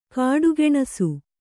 ♪ kāḍu geṇasu